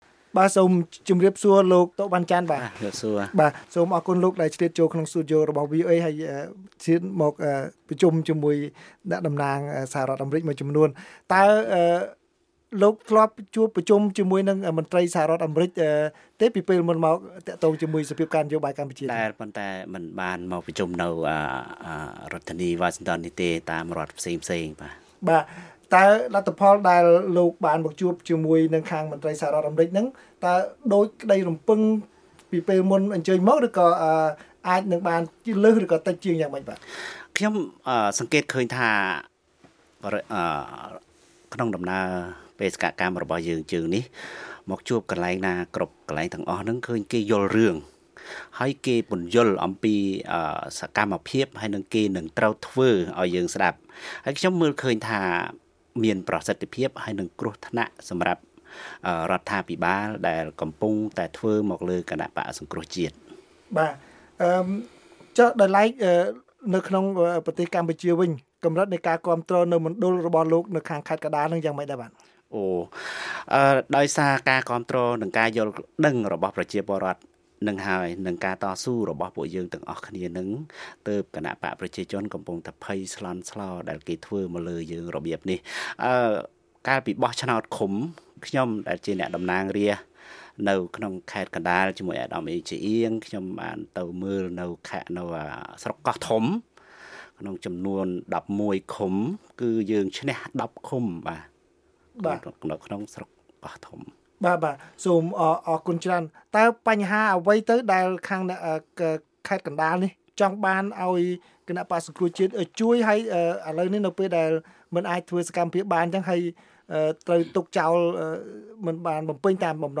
បទសម្ភាសVOA៖ លោក តុ វ៉ាន់ចាន់ចូលរួមយុទ្ធនាការក្រៅប្រទេសដើម្បីដោះស្រាយវិបត្តិនយោបាយកម្ពុជា